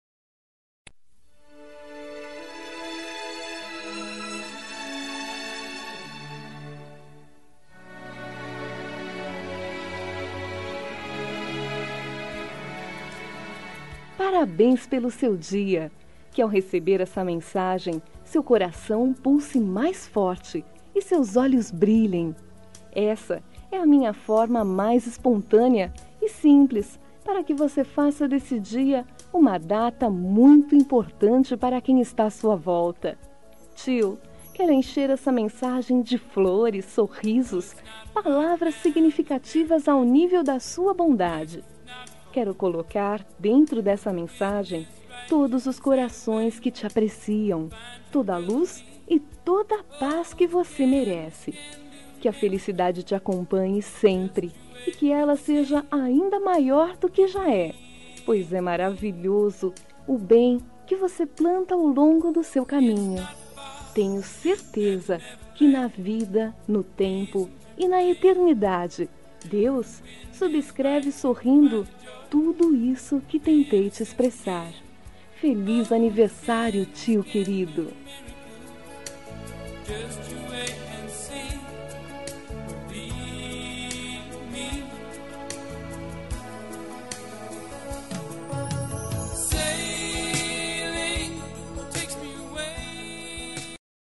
Aniversário de Tio – Voz Feminina – Cód: 931
931-tio-fem.m4a